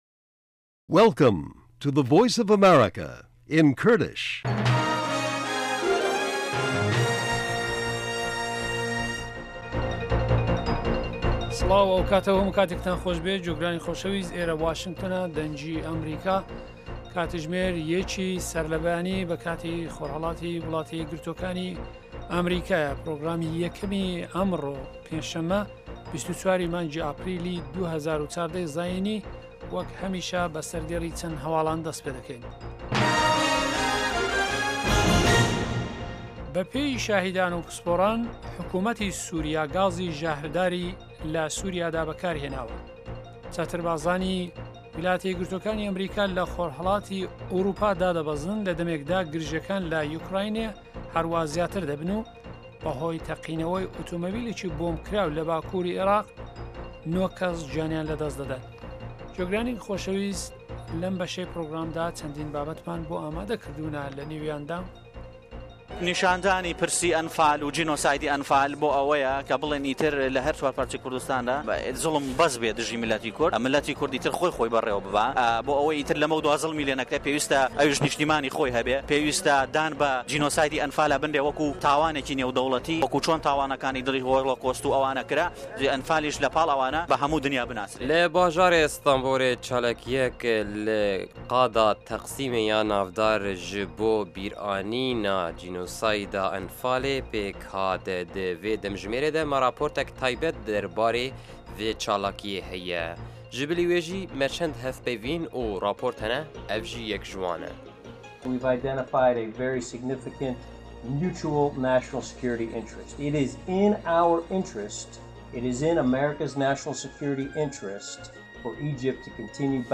بابه‌ته‌كانی ئه‌م كاتژمێره بریتین له هه‌واڵه‌كان، ڕاپۆرتی په‌یامنێران، گفتوگۆ و شیكردنه‌وه، ئه‌مڕۆ له مێژووی ئه‌مه‌ریكادا، هه‌روههاش بابهتی ههمهجۆری هونهری، زانستی و تهندروستی، ئابوری، گهشتێك به نێو ڕۆژنامه جیهانییهكاندا، دیدوبۆچونی واشنتۆن، گۆرانی كوردی و ئهمهریكی و بهرنامهی ئهستێره گهشهكان له ڕۆژانی ههینیدا.